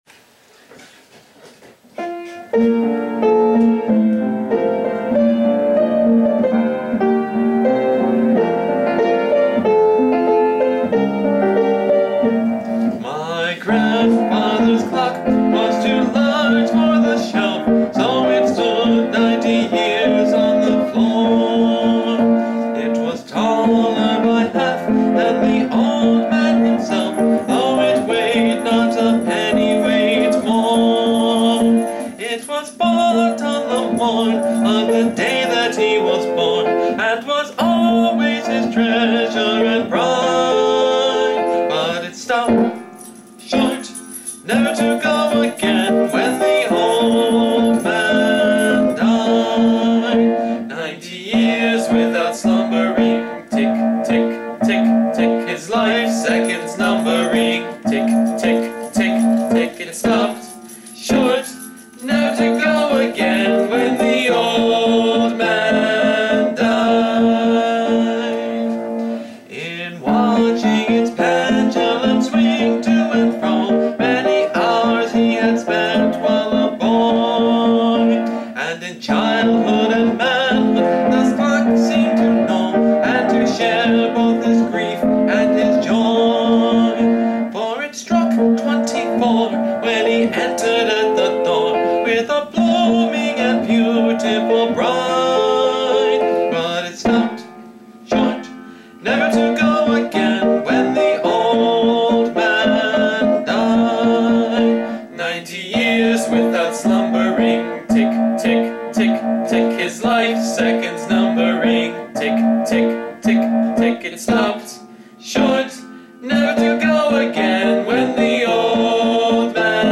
Published in 1876, this song was one of the most popular of its era, As the commentary in the book describes it: “The song is still beguiling in its simplicity, naturalness, humor and th easy flow of melody and accompaniment.”